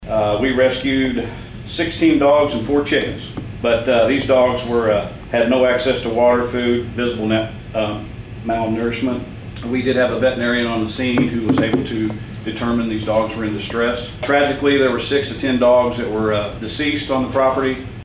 Sheriff Jeff Crites told the County Commission they were able to rescue some animals, but not all of them.